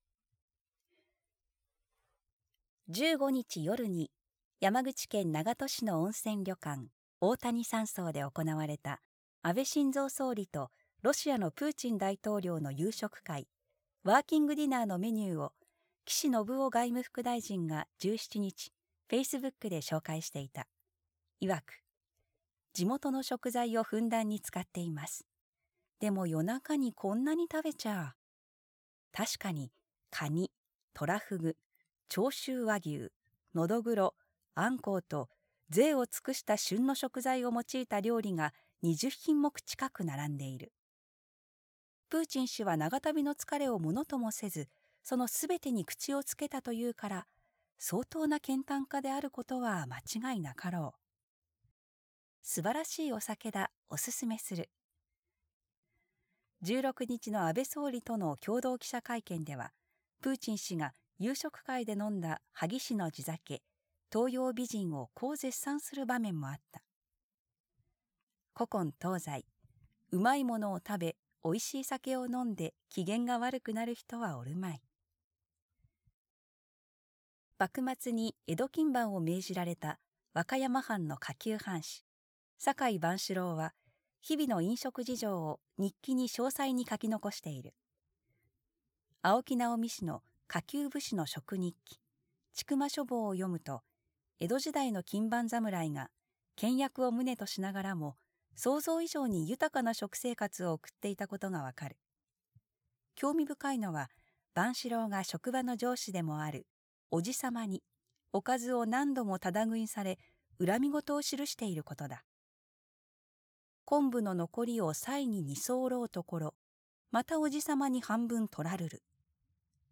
全国240名の登録がある局アナ経験者がお届けする番組「JKNTV」